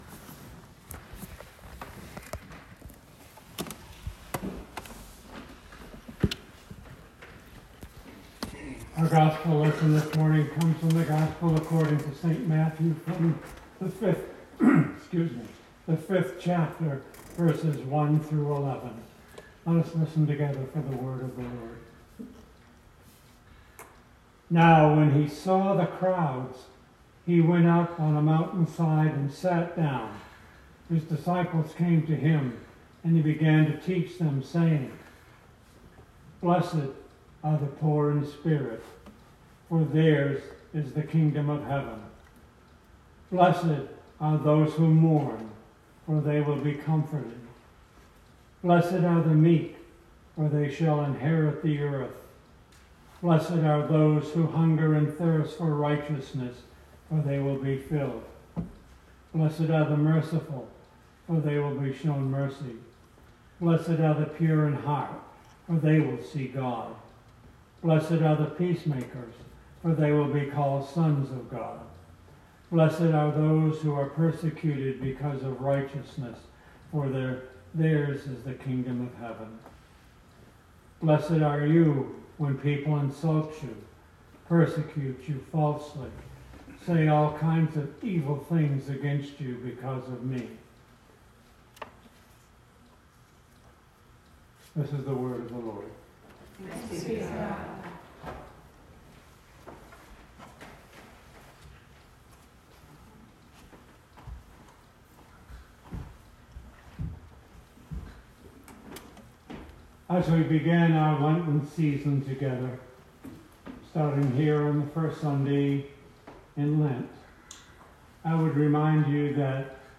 Sermon 2020-03-01